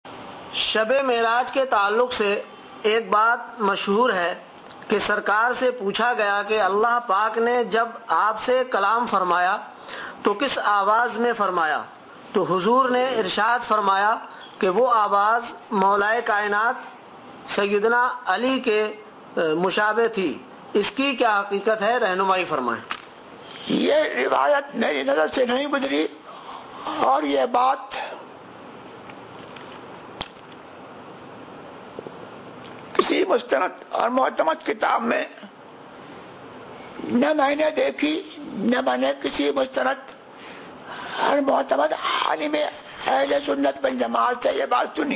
▶Answer (Voice Recording):